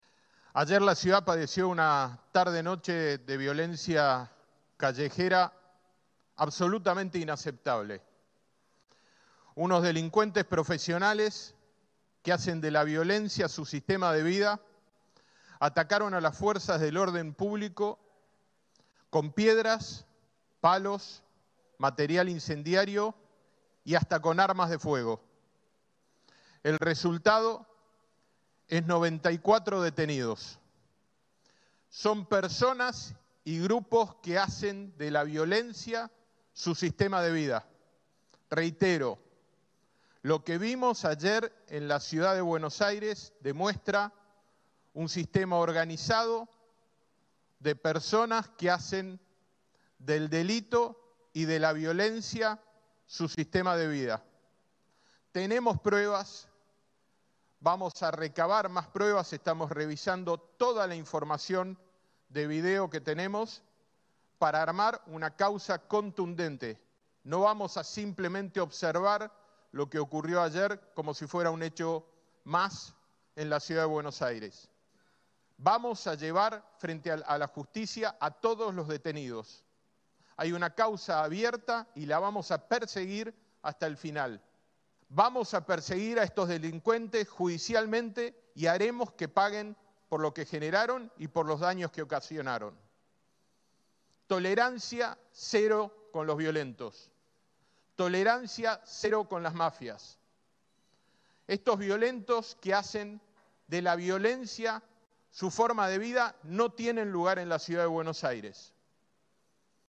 Jorge Macri informó en conferencia de prensa en el Centro de Monitoreo Urbano de Chacarita sobre el operativo y las medidas que tomó la Ciudad.